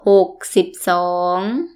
_ hogg _ sibb ∨ soong